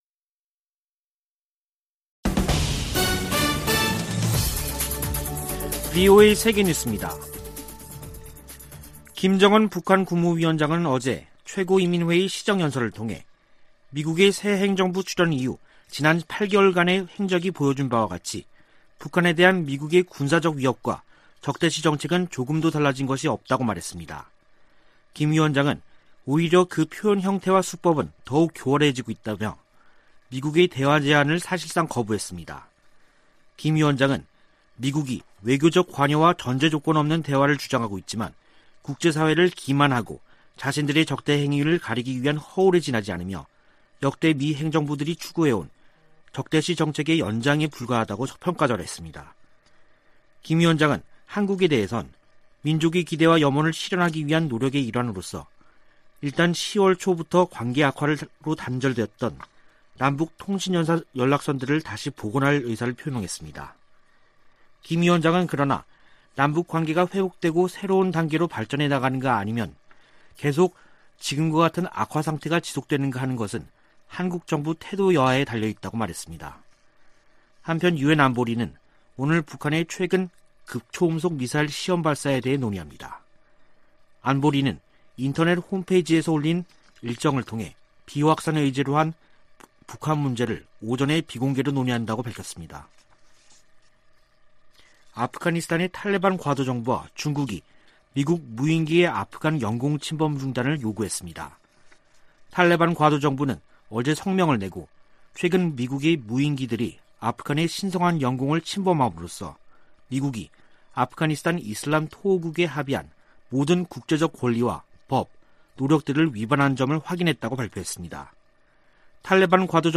VOA 한국어 간판 뉴스 프로그램 '뉴스 투데이', 2021년 9월 30일 2부 방송입니다. 김정은 북한 국무위원장은 조 바이든 행정부에서도 미국의 대북 적대시 정책이 변한 게 없다며 조건 없는 대화 재개를 거부했습니다. 미 국무부는 북한에 적대적 의도가 없다고 거듭 강조했습니다.